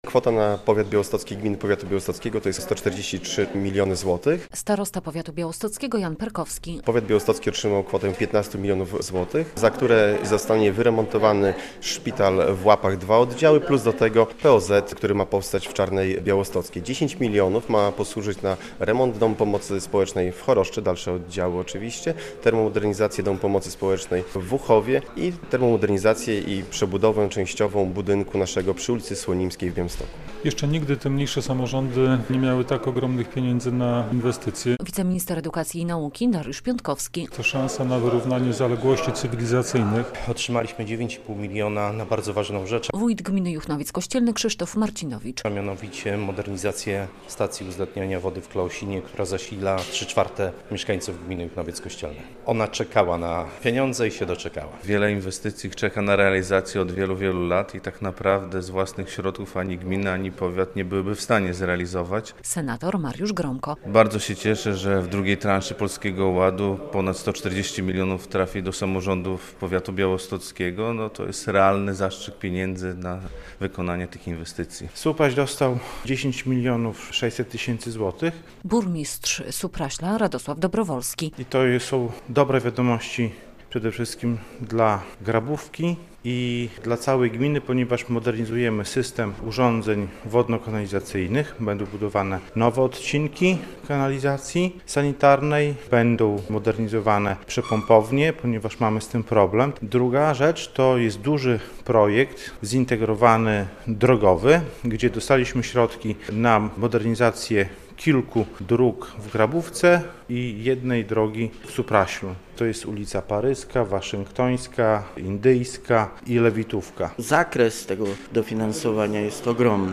relacja
W poniedziałek (13.06) w Starostwie Powiatowym w Białymstoku odbyło się wręczenie symbolicznych czeków gminom z tego powiatu, które pozyskały środki z drugiej edycji rządowego Funduszu Inwestycji Strategicznych (FIS).